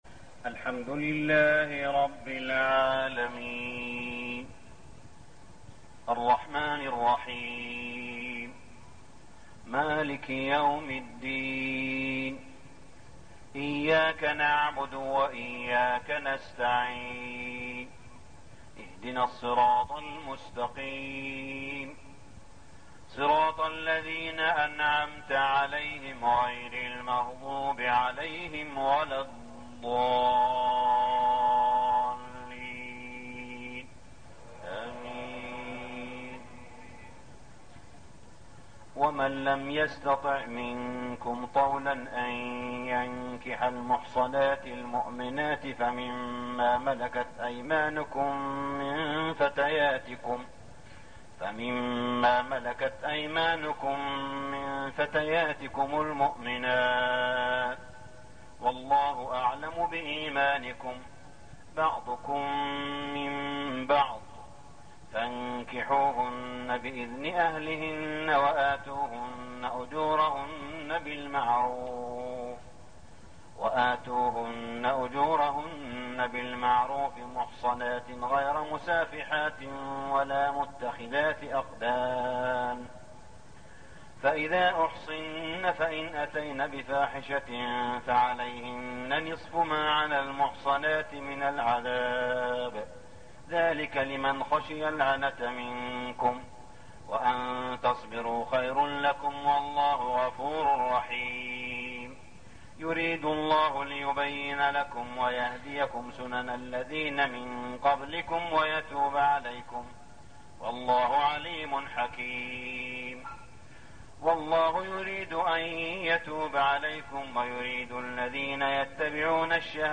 صلاة التراويح ليلة 5-9-1413هـ | سورة النساء 25-87 > تراويح الحرم المكي عام 1413 🕋 > التراويح - تلاوات الحرمين